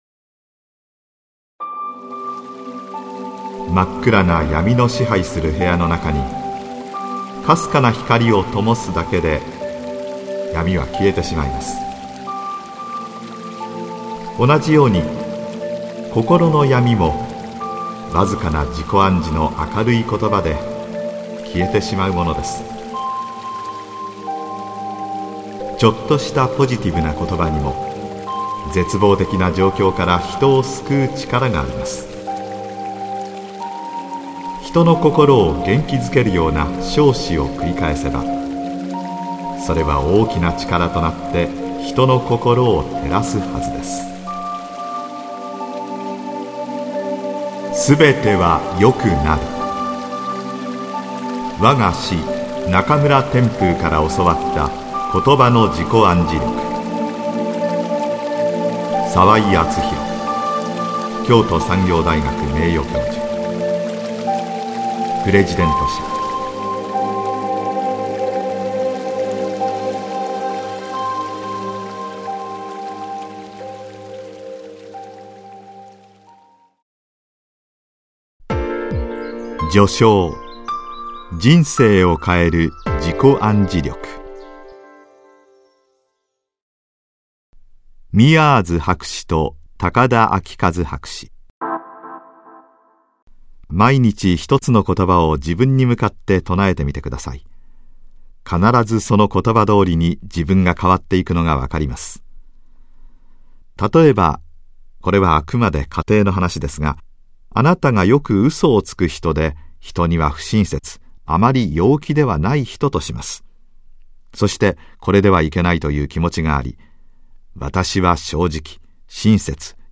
[オーディオブックCD] すべてはよくなる ― わが師中村天風から教わったことばの自己暗示力